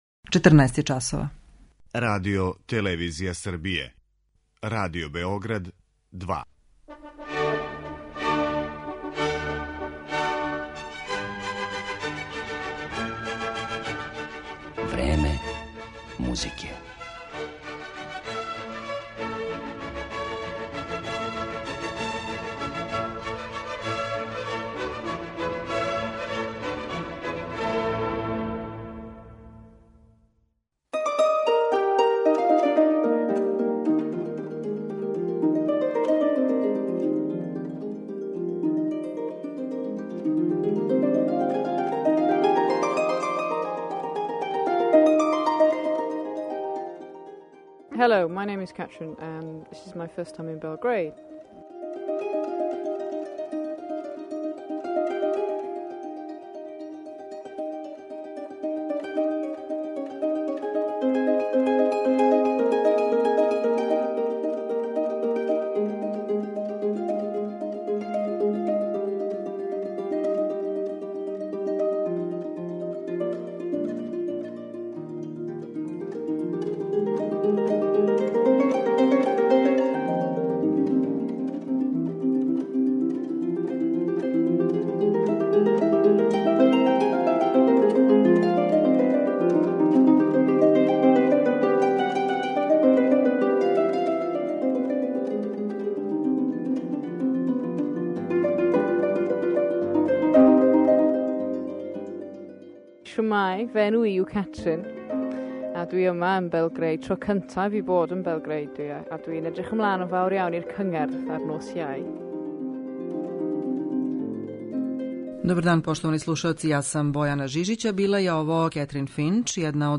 Кетрин Финч, харфисткиња